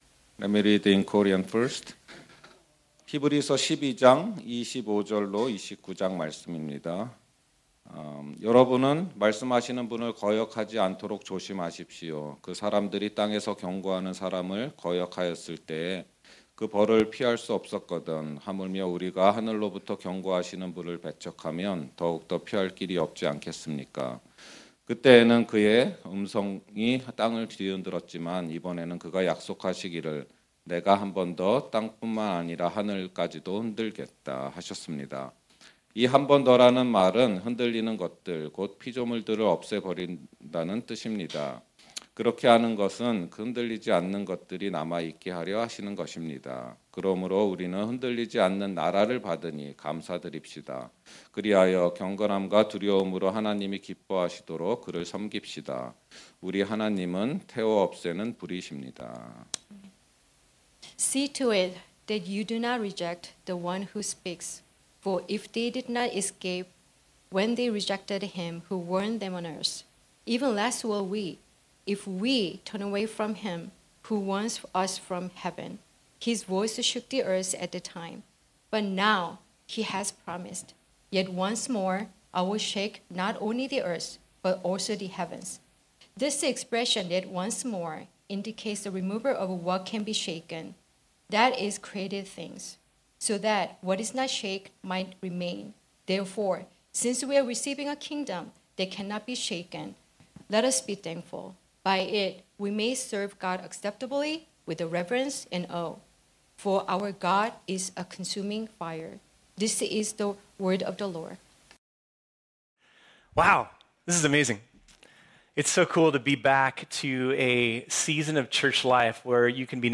This sermon was originally preached on Sunday, March 5, 2023.